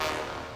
[ACD] - Lex Hit.wav